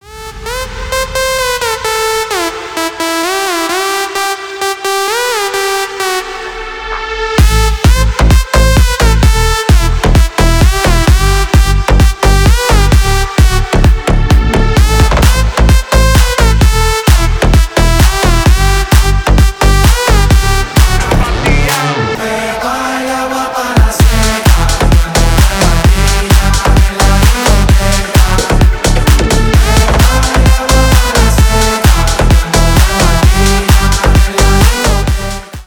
Громкие рингтоны / Клубные рингтоны